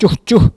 Cri pour appeler les cochons ( prononcer le cri )
Langue Maraîchin